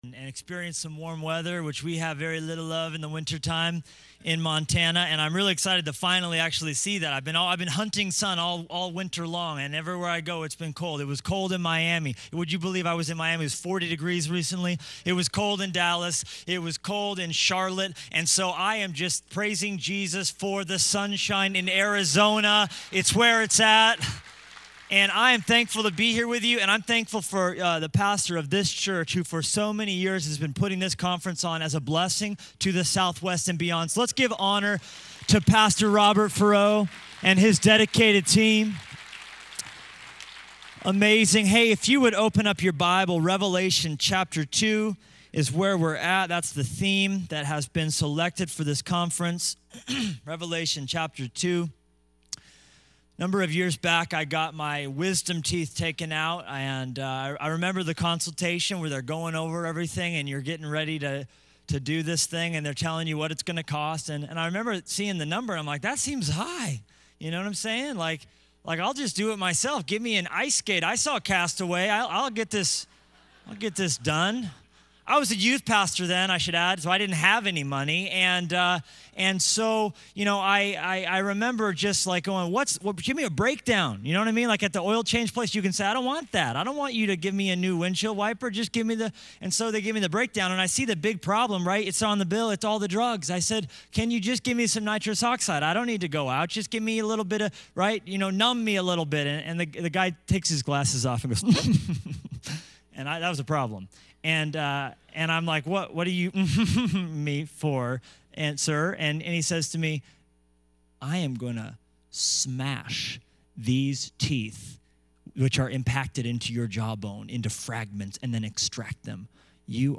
at the 2012 SW Pastors and Leaders Conference